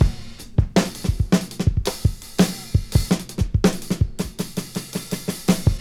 • 110 Bpm Fresh Drum Loop F Key.wav
Free drum groove - kick tuned to the F note. Loudest frequency: 1006Hz
110-bpm-fresh-drum-loop-f-key-Y0d.wav